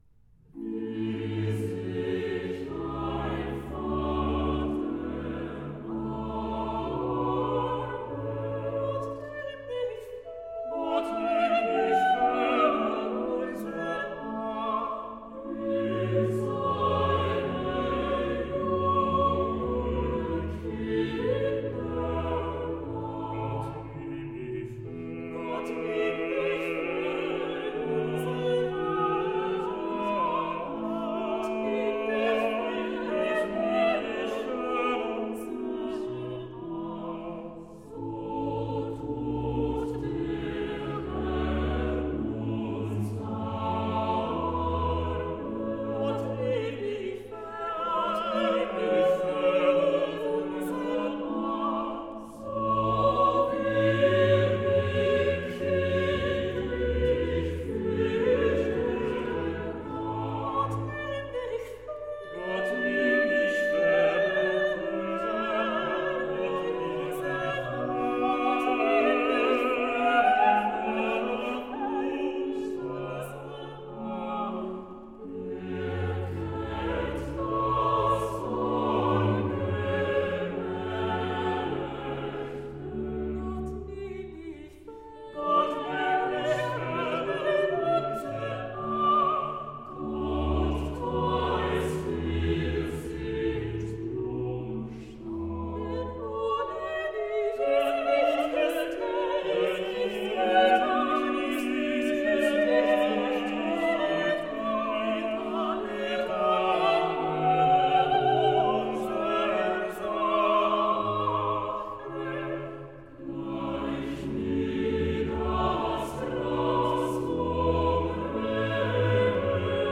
Motet for Eight Voices in double Choir.
Soprano.
Alto.
Tenor.
Baritone